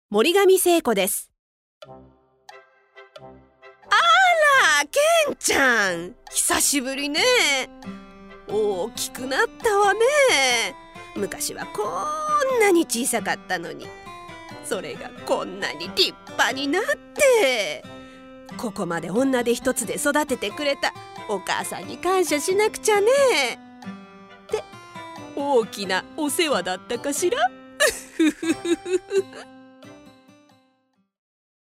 ボイスサンプル
• 穏やかでまろやかな声
• 音域：高～中音
• 声の特徴：穏やか、明るい、説得力